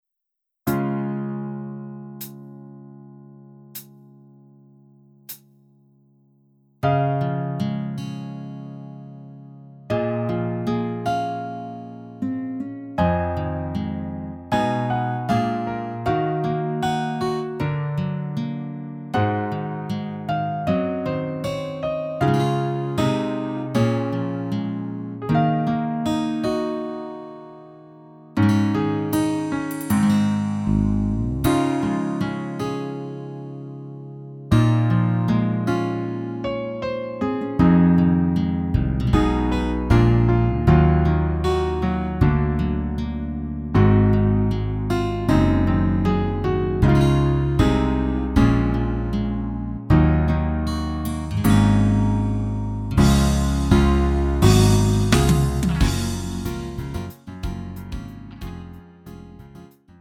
음정 원키 3:45
장르 구분 Lite MR
Lite MR은 저렴한 가격에 간단한 연습이나 취미용으로 활용할 수 있는 가벼운 반주입니다.